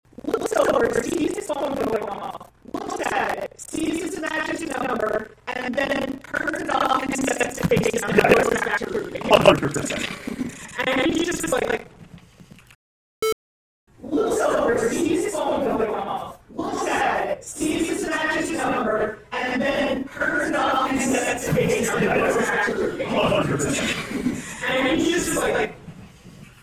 Can do a sort of comb-over : applying temporal-blur to spread the sound into the bald drop-out gaps, but the result is even less intelligible …